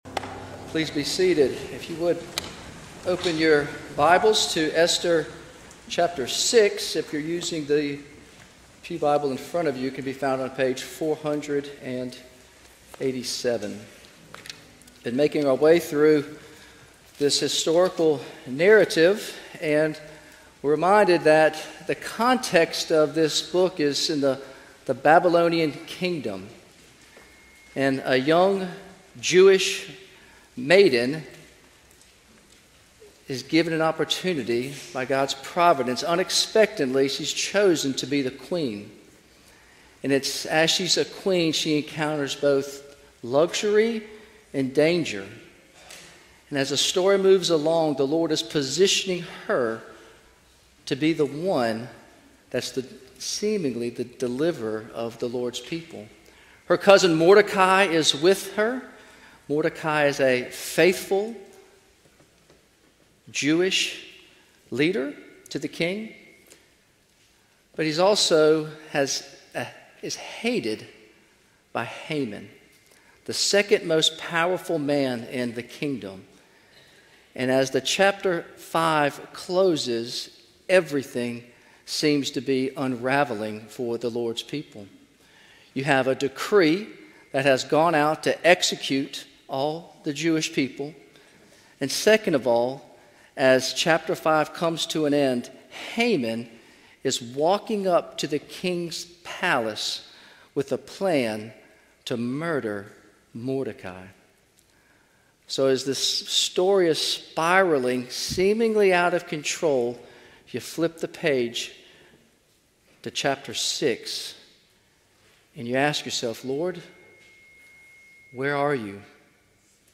A sermon from the series "Esther."